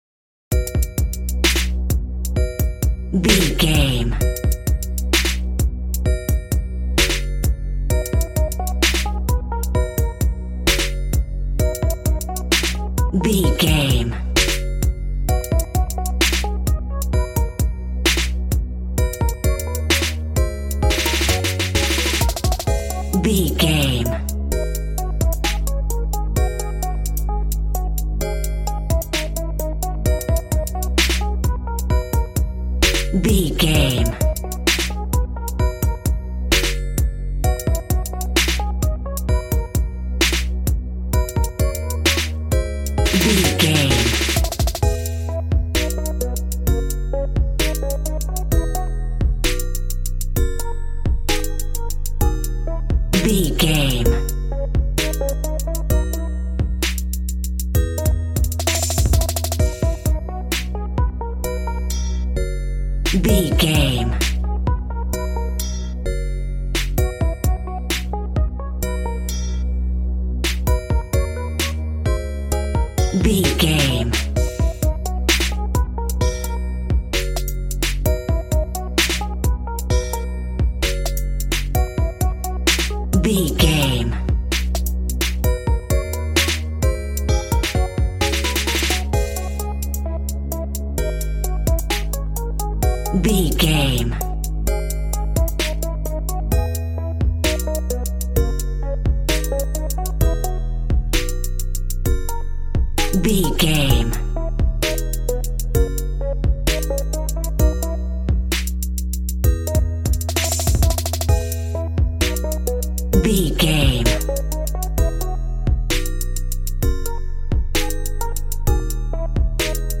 Ionian/Major
calm
smooth
synthesiser
piano